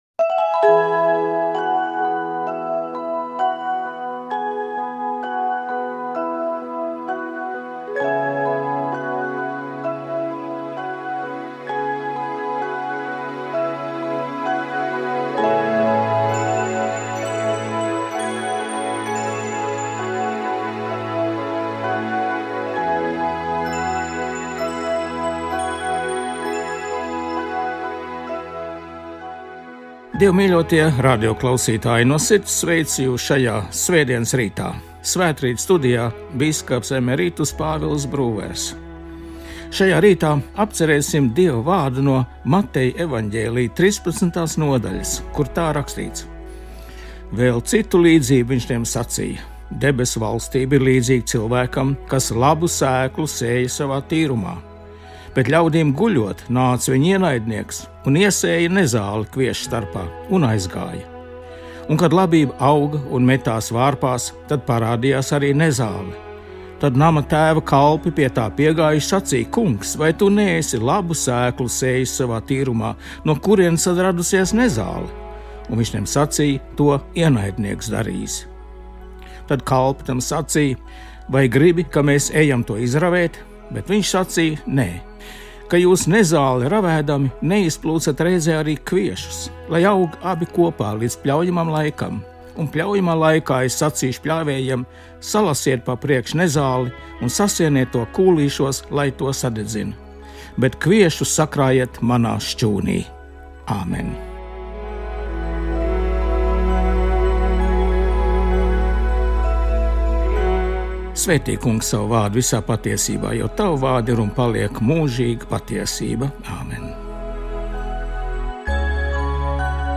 Svētrīts. Studijā bīskaps emeritus Pāvils Brūvers